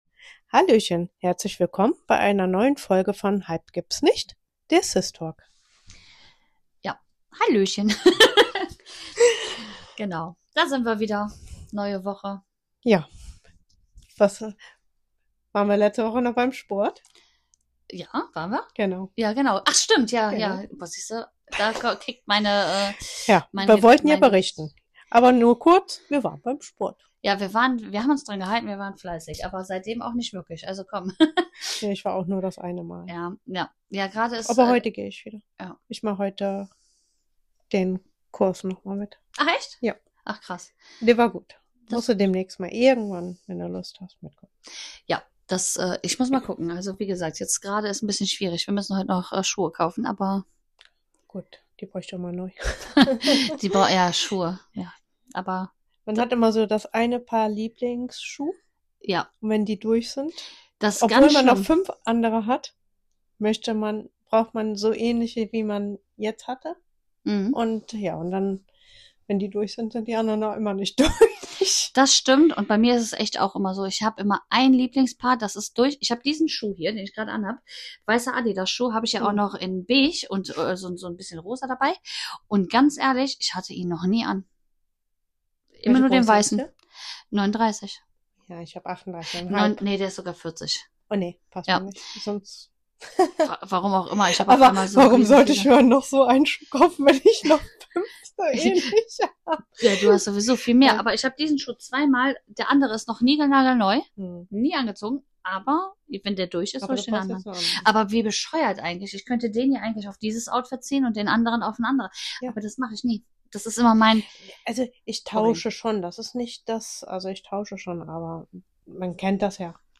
Zwei Schwestern, ein Podcast-Mikro – und komplett unterschiedliche Meinungen über Frisuren und Schuhe.